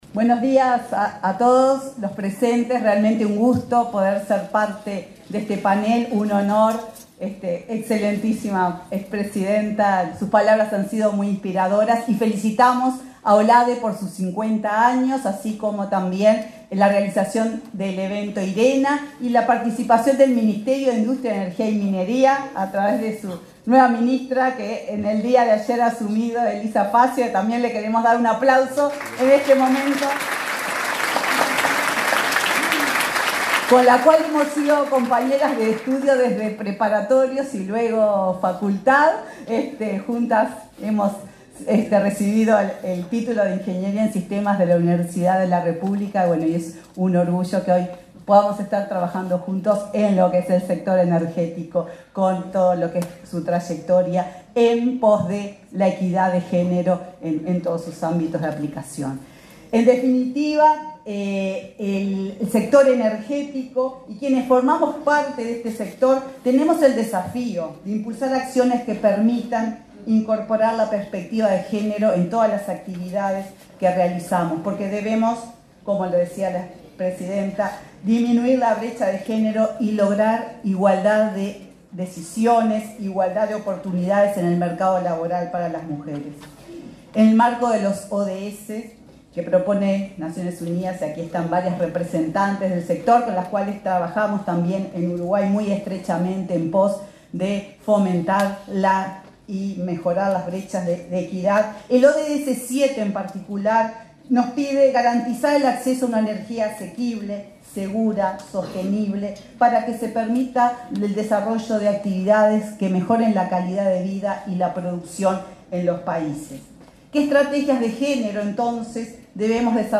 Palabras de la presidenta de UTE, Silvia Emaldi
Palabras de la presidenta de UTE, Silvia Emaldi 07/11/2023 Compartir Facebook X Copiar enlace WhatsApp LinkedIn La presidenta de UTE, Silvia Emaldi, expuso, este martes 7 en Montevideo, en el panel sobre evaluación y resultados de políticas y estrategias de equidad de género en el sector, en el marco del Primer Foro de Inversión en Transición Energética para América Latina de la Agencia Internacional de Energías Renovables (Irena).